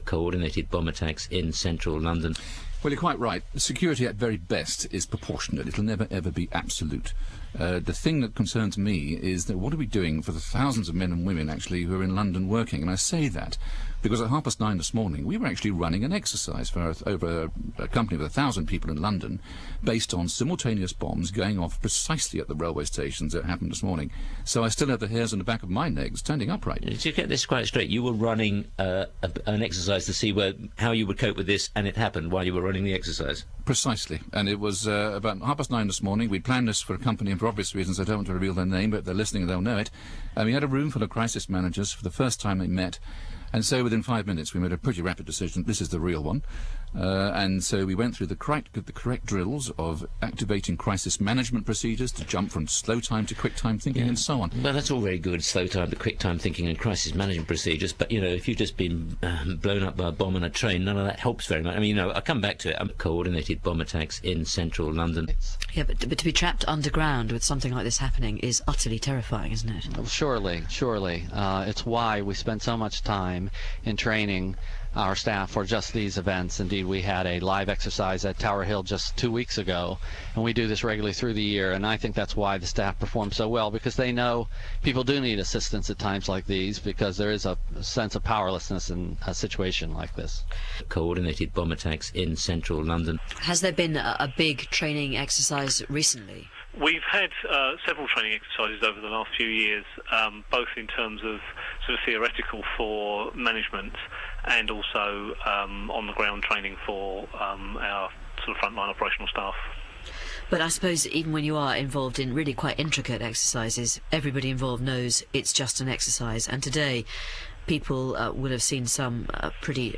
Compilation interviews (short) - RealMedia 954K